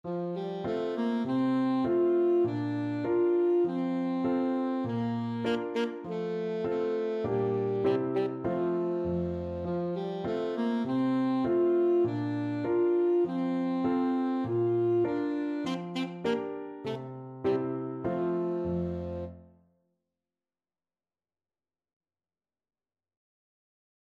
Alto Saxophone
4/4 (View more 4/4 Music)
Moderato